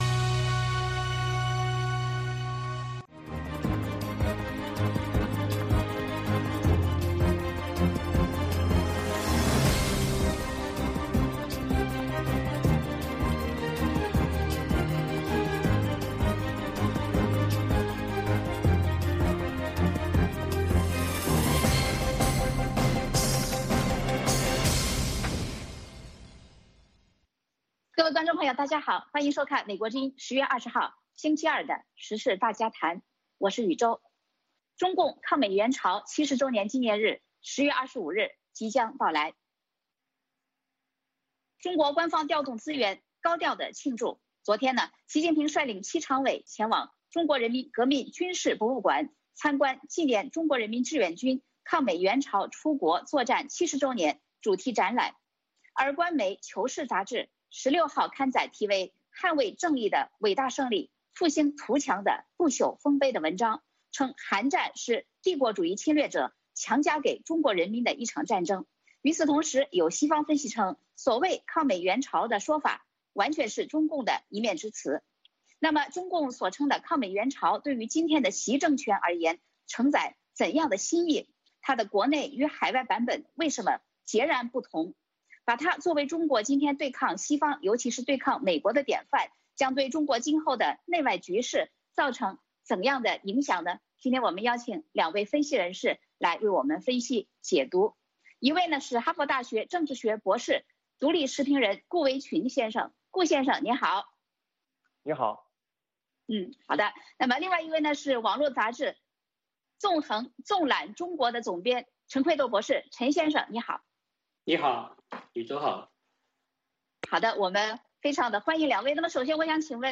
嘉宾